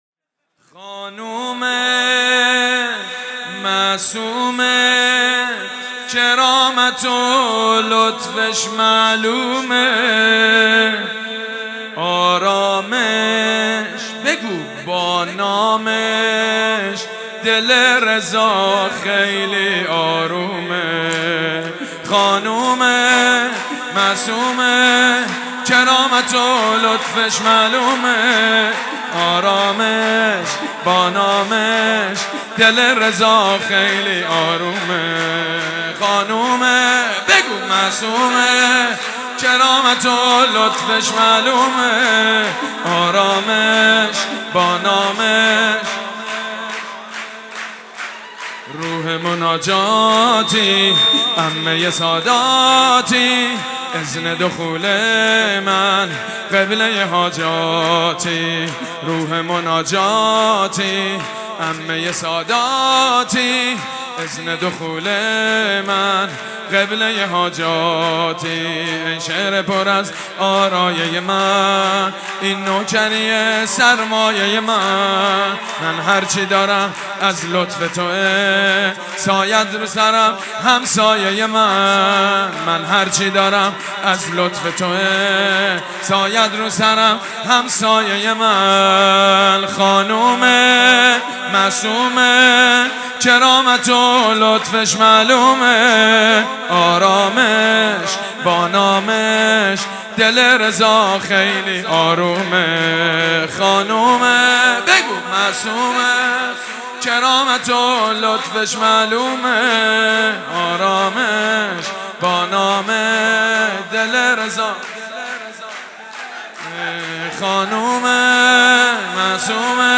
سرود: خانومه معصومه، کرامت و لطفش معلومه